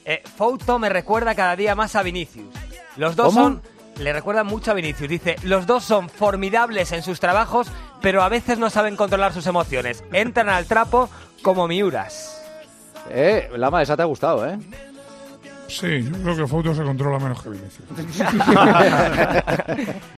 Un mensaje durante el programa desata las risas entre los contertulios con un curioso parecido entre el periodista y el jugador del Real Madrid.